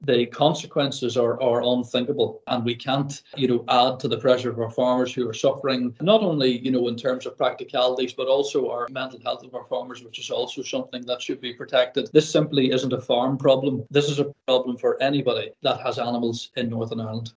gave evidence to it’s inquiry: